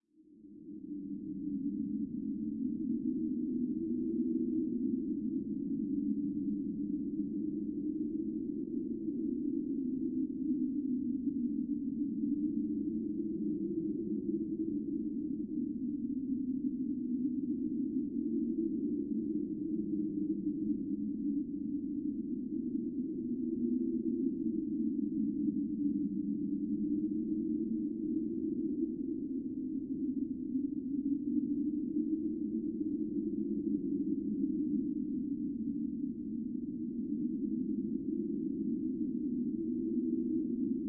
Звук суровой зимней тишины после ядерного взрыва